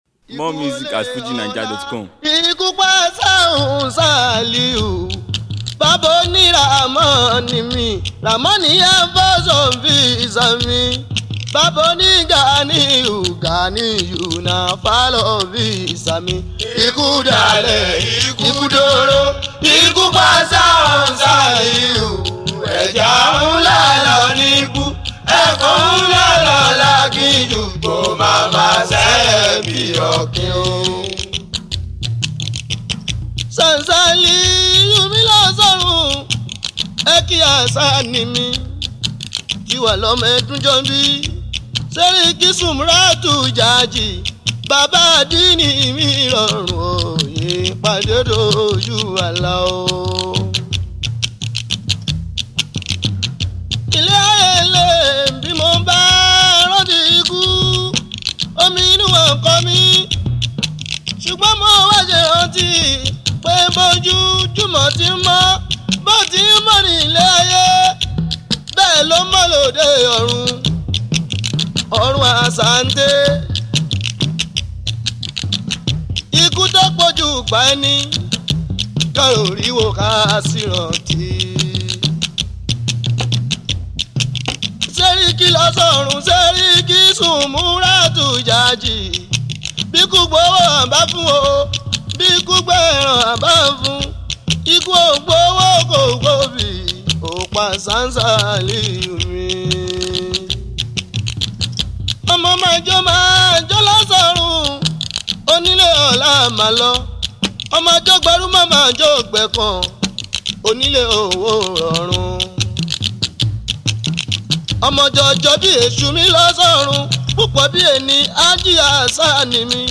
Fuji